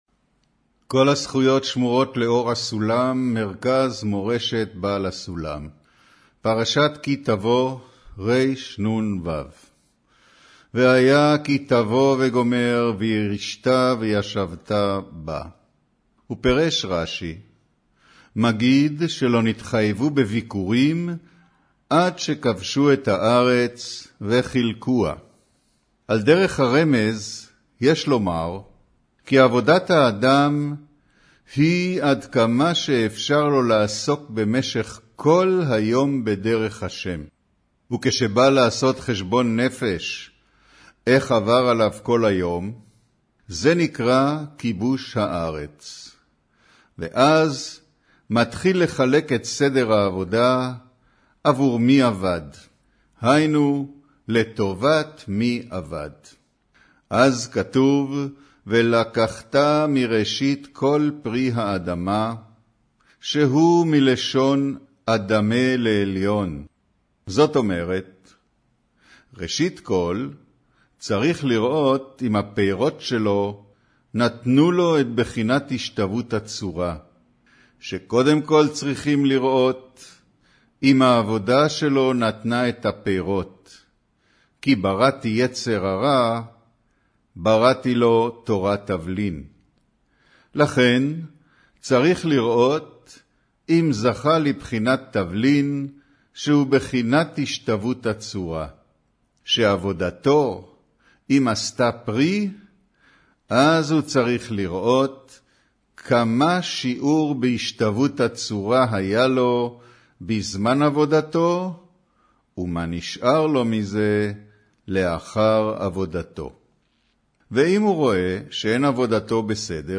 אודיו - קריינות פרשת כי תבוא